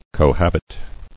[co·hab·it]